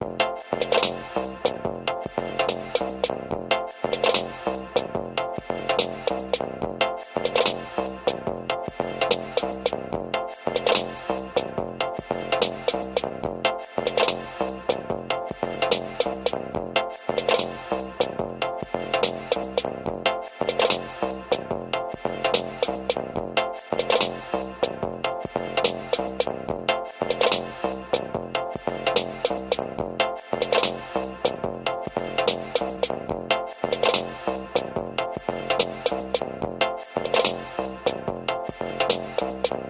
ループサウンド。
クリアーな音色でスマホのスピーカーにあった音です。